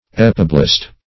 Search Result for " epiblast" : The Collaborative International Dictionary of English v.0.48: Epiblast \Ep"i*blast\, n. [Pref. epi- + -blast.]
epiblast.mp3